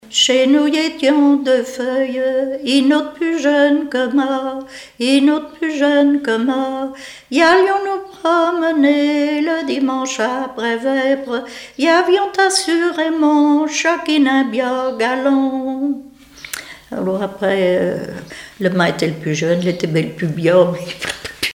Deux chansons traditionnelles
Pièce musicale inédite